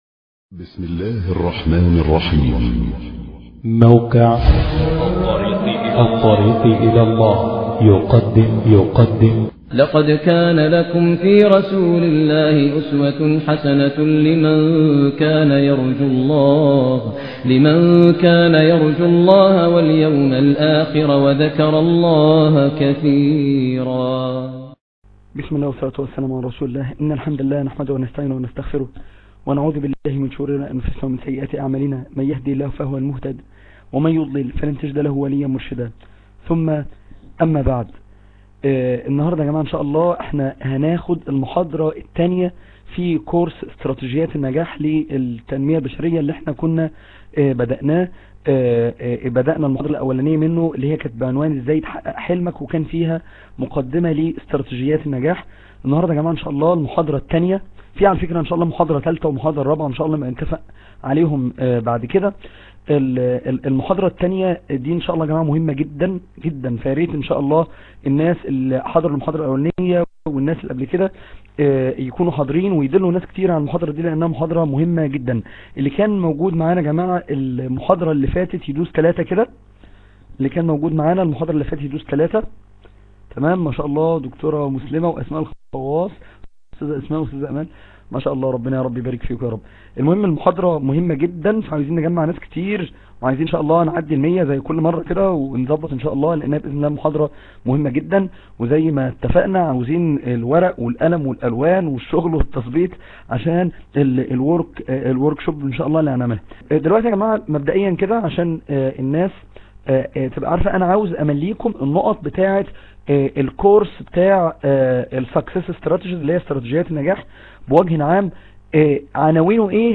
عنوان المادة : 3 الأسباب العشرة للنجاح دروس : الغرفة الدعوية تاريخ التحميل : الأثنين 24 فبراير 2014 مـ حجم المادة : 51.08 ميجا بايت عدد الزيارات : 2147 زيارة عدد مرات الحفظ : 1070 مرة سماع المادة حفظ المادة